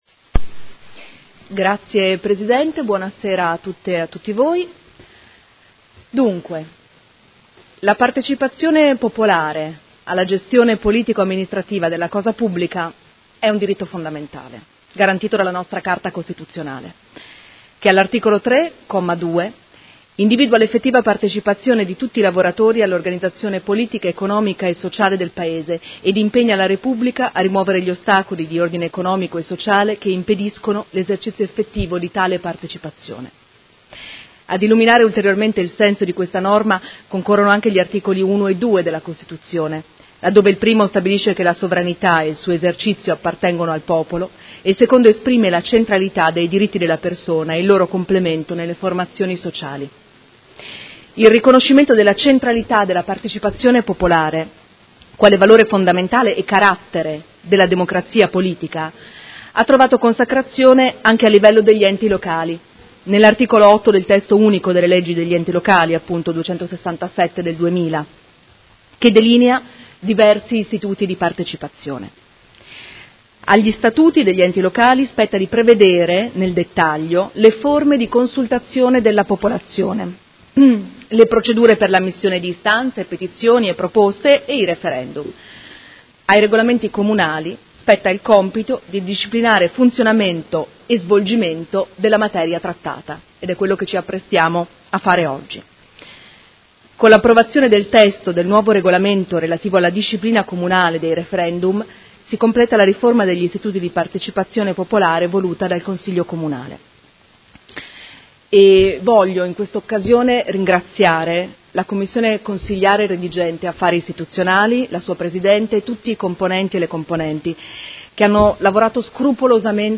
Seduta del 15/11/2018 Delibera. Revisione del Regolamento per la disciplina dei Referendum del Comune di Modena